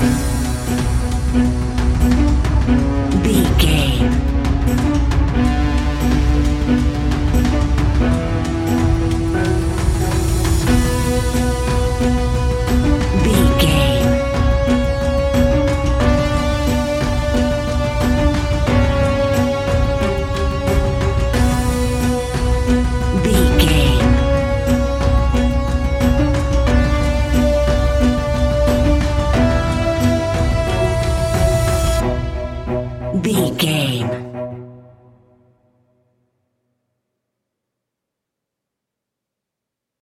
In-crescendo
Thriller
Aeolian/Minor
ominous
dark
haunting
eerie
strings
percussion
piano
drums
ticking
electronic music
Horror Synths